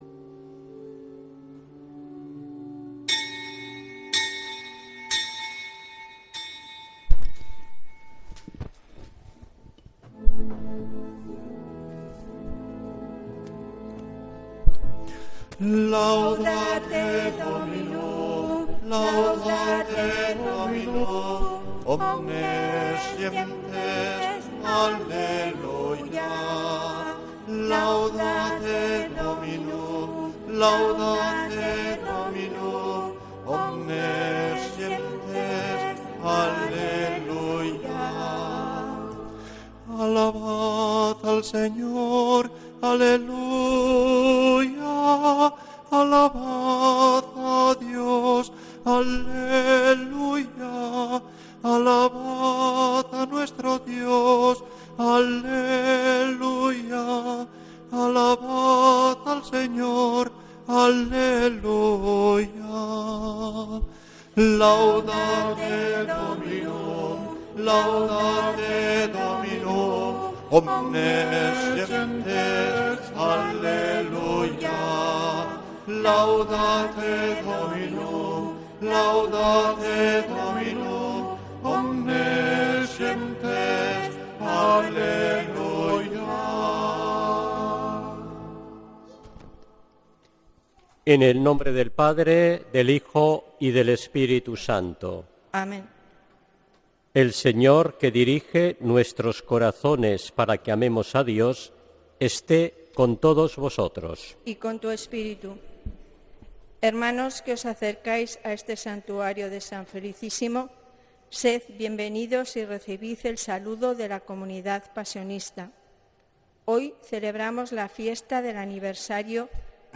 Santa Misa desde San Felicísimo en Deusto, domingo 9 de noviembre de 2025